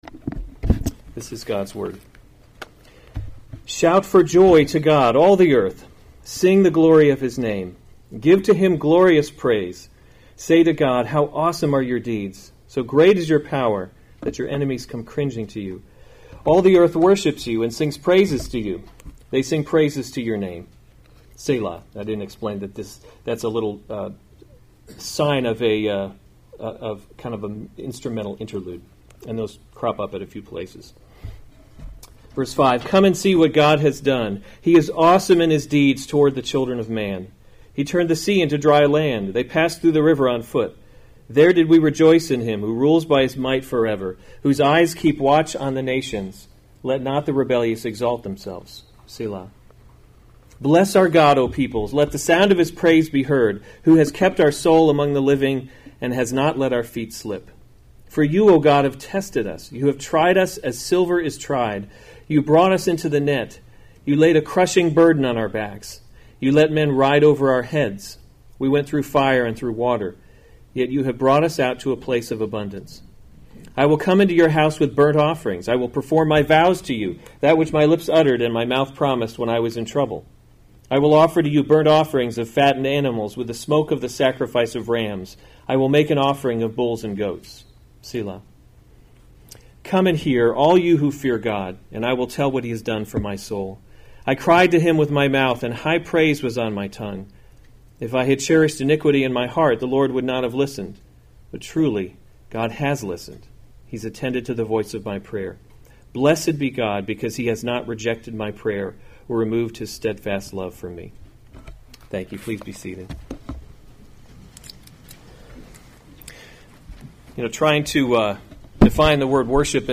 July 7, 2018 Psalms – Summer Series series Weekly Sunday Service Save/Download this sermon Psalm 66 Other sermons from Psalm How Awesome Are Your Deeds To the choirmaster.